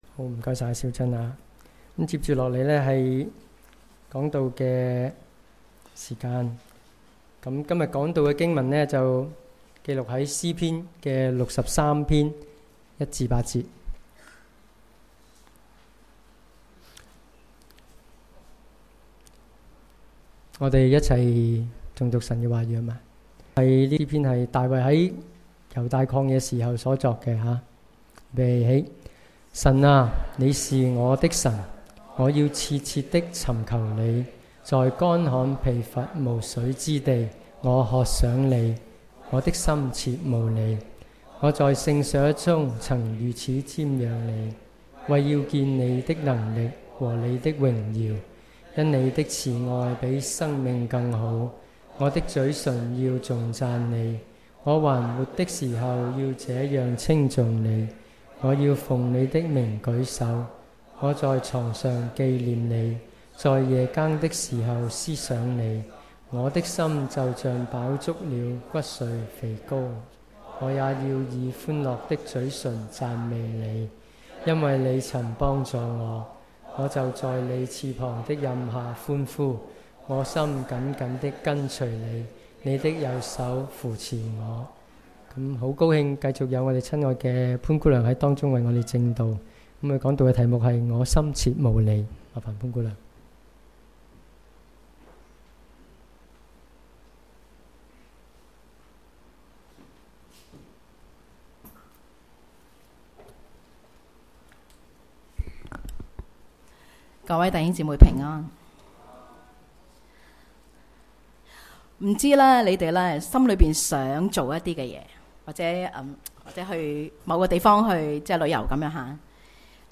主日崇拜講道 – 我的心切慕主